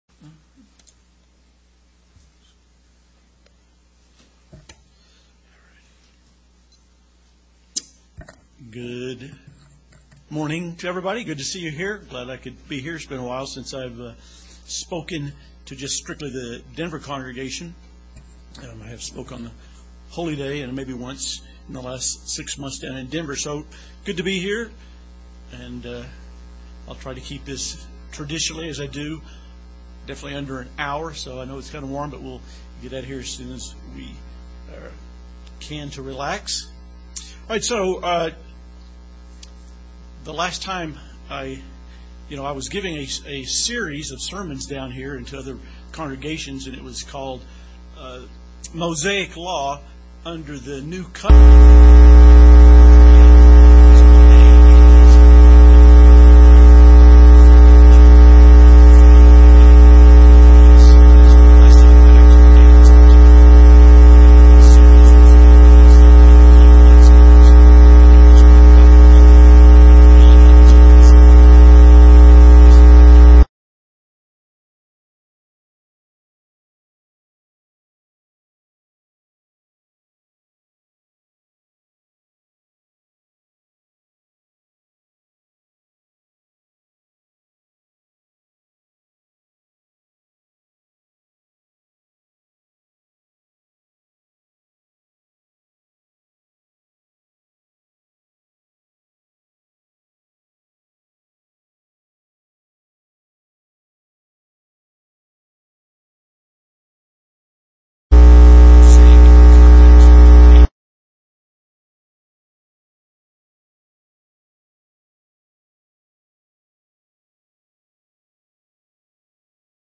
Sermons
Given in Colorado Springs, CO Denver, CO Loveland, CO